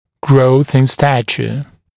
[grəuθ ɪn ‘stæʧə][гроус ин ‘стэчэ]увеличение роста (человека)